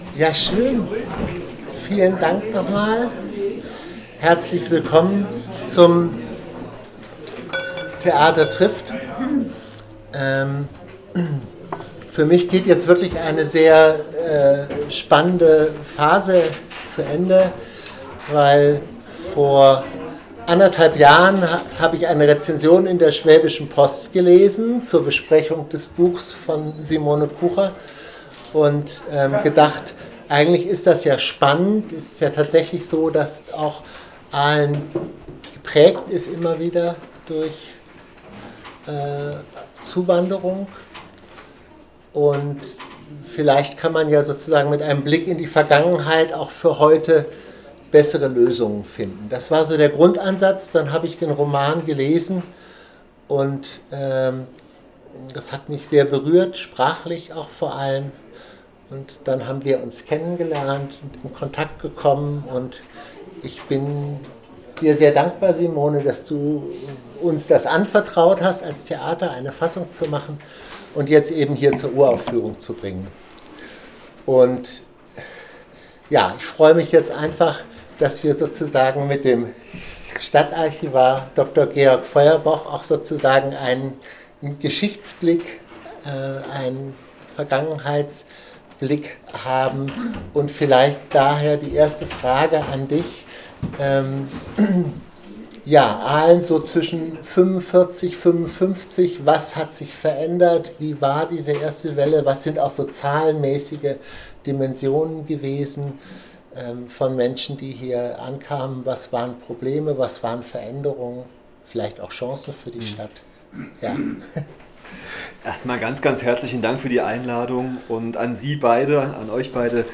Wir laden Expert*innen und Interessierte zur Diskussion ein, die mit einem Podium beginnt, um dem Publikum einen Anstoß für kritische Fragen und /oder das direkte Gespräch bei einem Getränk zu bieten.